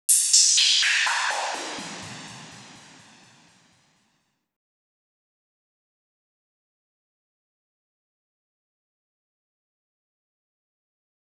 FX - BAPTIZED IN FIRE.wav